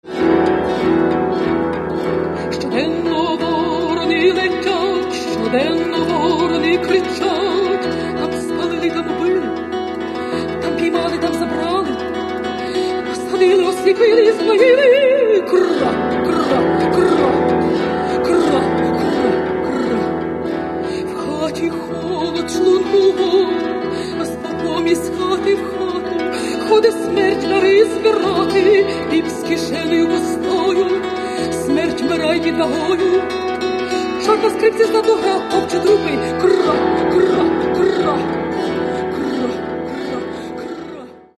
Каталог -> Народна -> Бандура, кобза тощо
Відома бандуристка